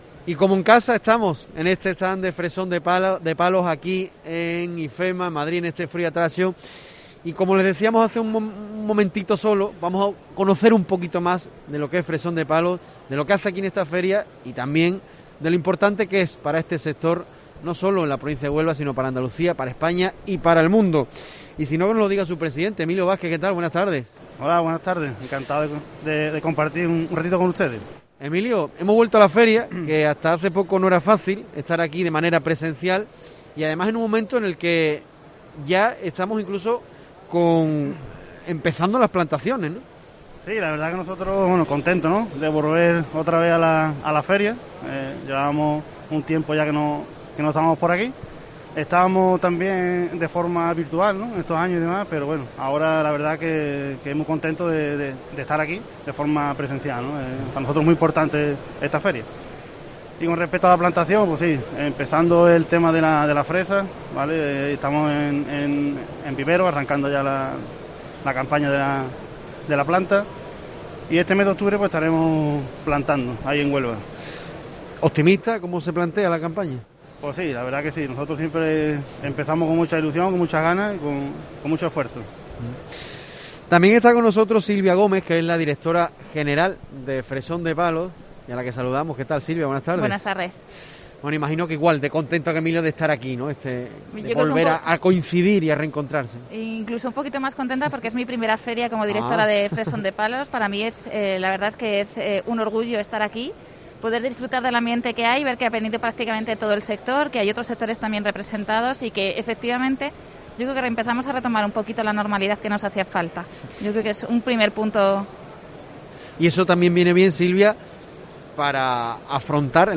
Atendiendo a los micrófonos de COPE Huelva, desde su stand, la empresa ha reforzado el compromiso con una actividad responsable con el medio ambiente y el entorno que le rodea además de ligar investigación e innovación.